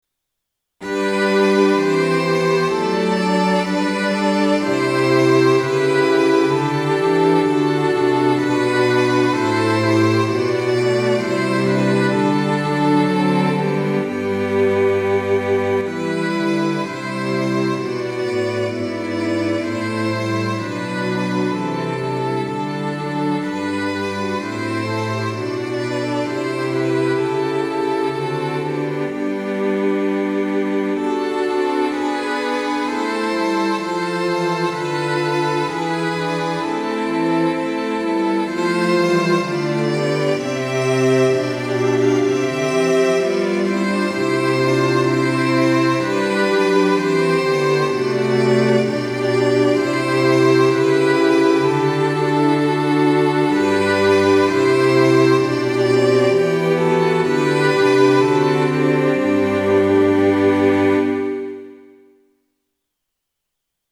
Sample Sound for Practice 練習用参考音源：MIDI⇒MP3　Version A.17
onality：G (♯)　Tempo：Quarter note = 64
1　 Strings 強弱付